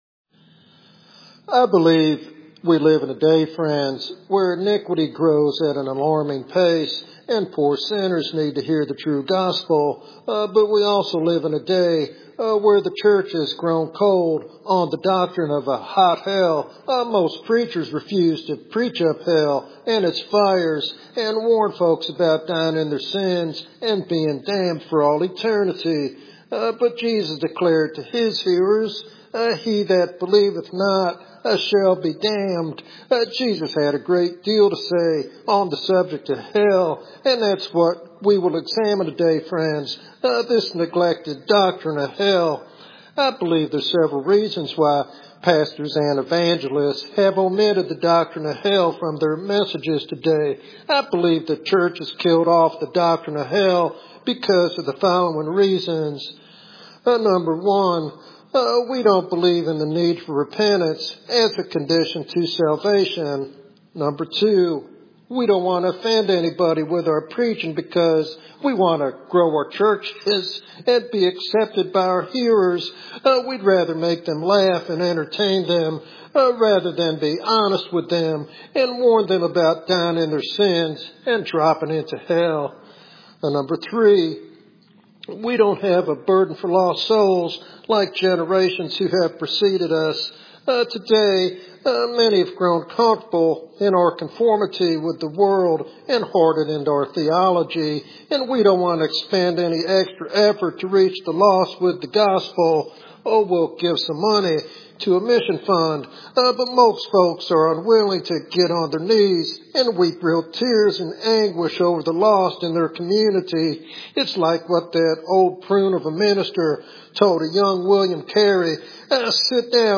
In this compelling sermon